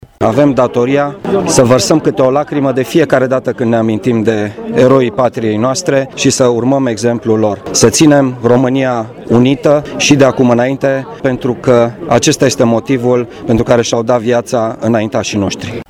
Ceremonialul central s-a desfășurat într-un loc tradițional, la Cimitirul Eroilor Români Sprenghi, unde au fost rostite alocuţiuni referitoare la însemnătatea Zilei Eroilor.
Prefectul Marian Rasaliu: